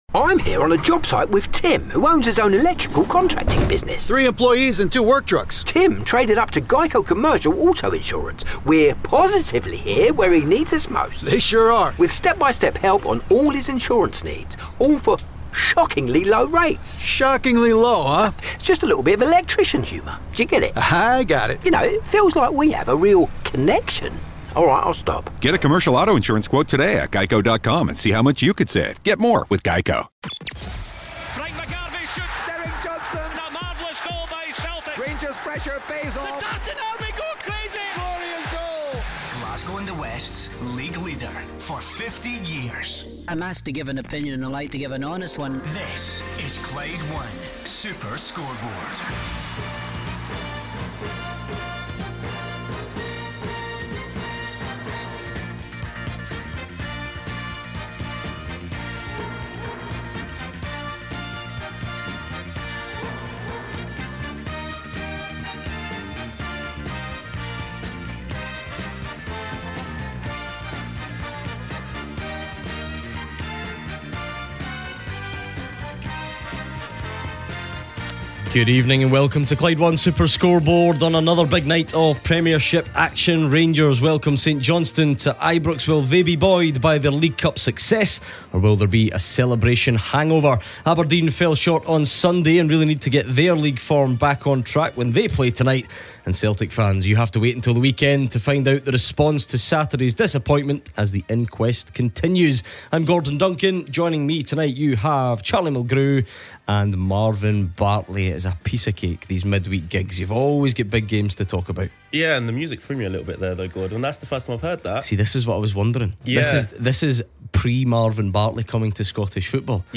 in the studio tonight, as we build up to two big games in the Scottish Premiership.